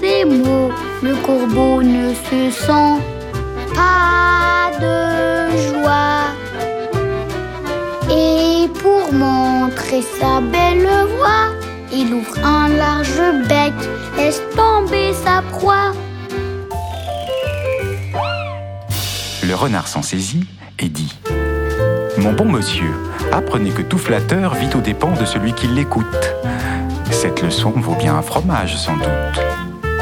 "templateExpression" => "Chansons pour enfants"